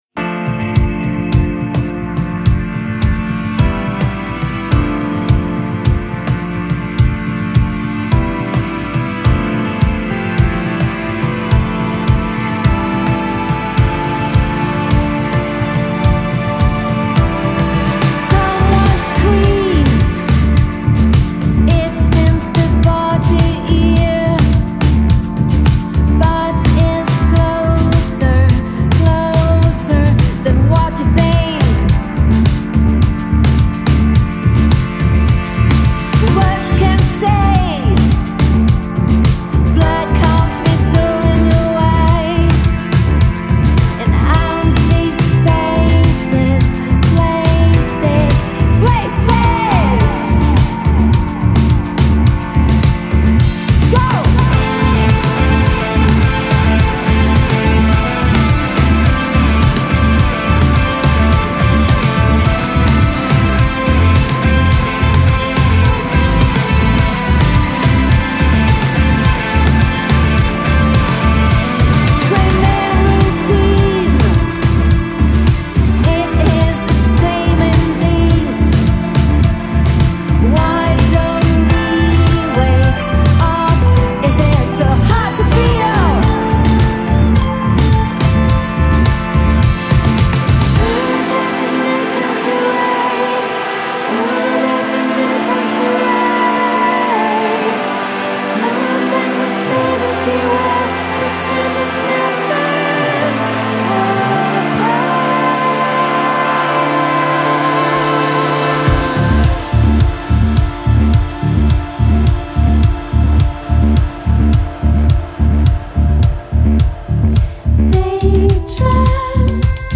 adroit mix of dream and drive
21st-century rock’n’roll